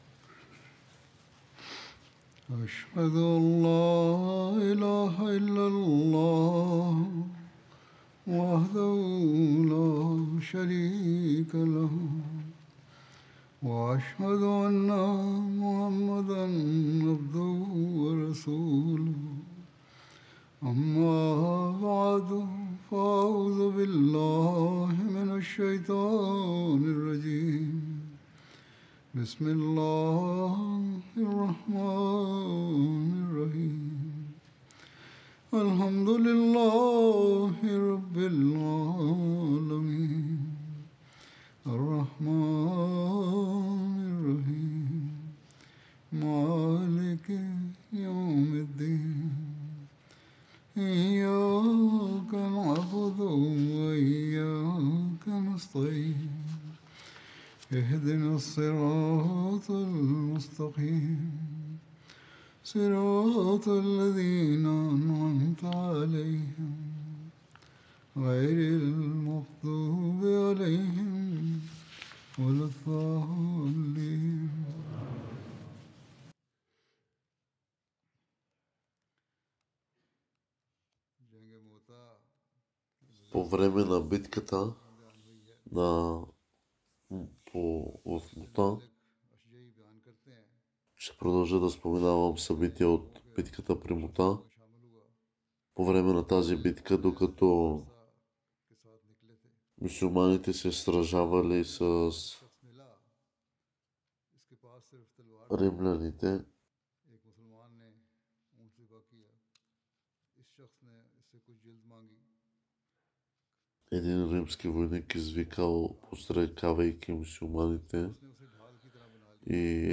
Bulgarian Translation of Friday Sermon